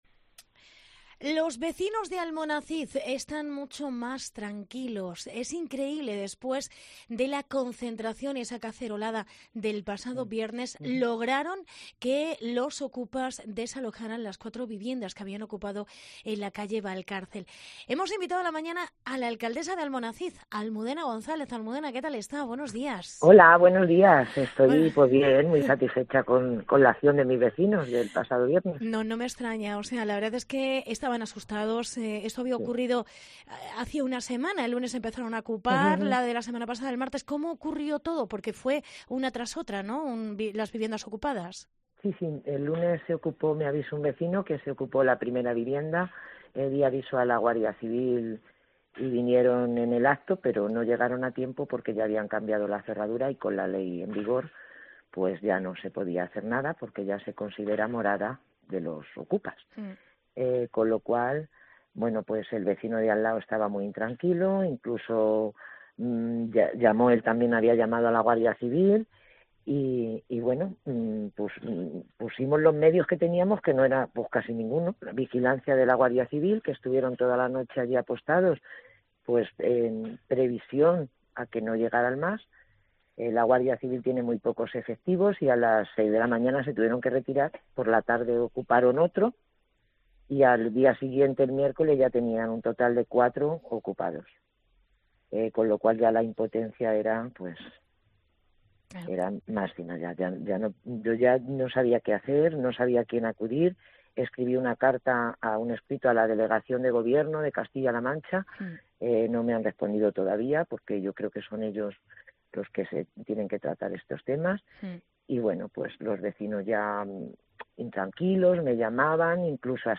Entrevista Almudena González. Alcaldesa de Almonacid